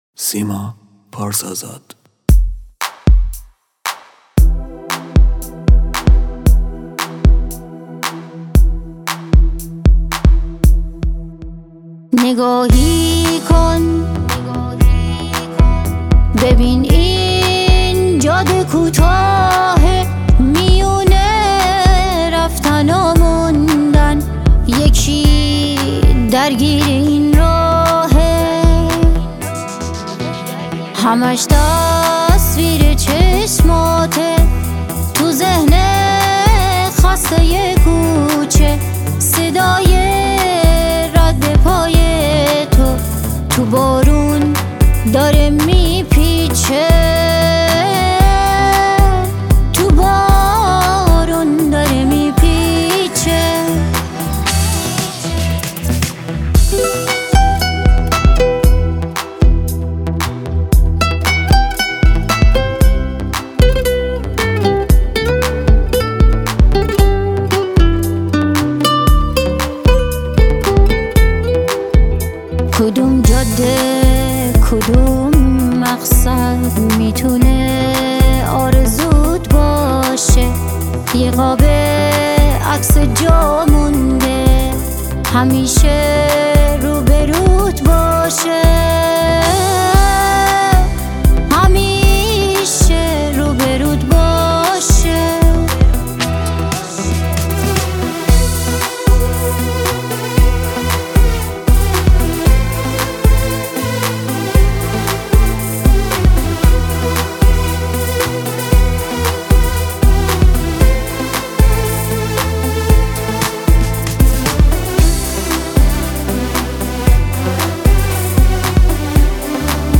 ترانه سرا و خواننده ایرانی
پاپ